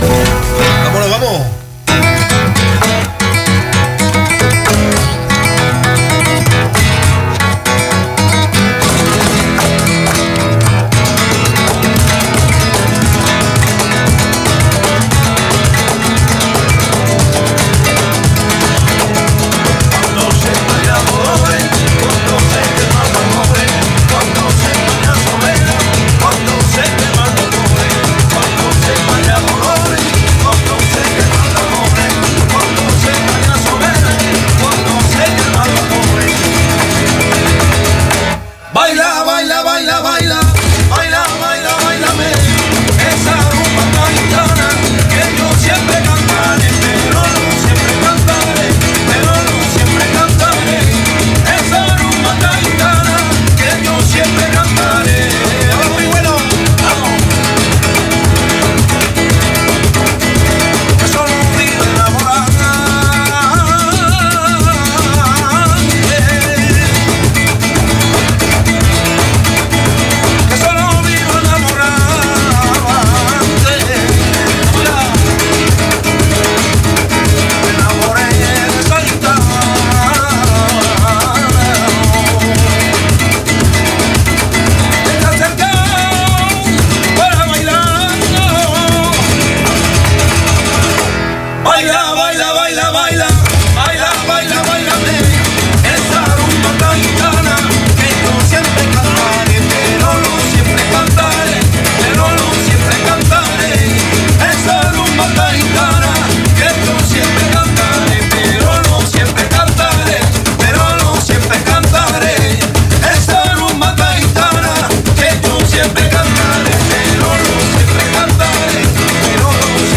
Héritier d’une longue lignée de musiciens gitans espagnols
Rumba fuego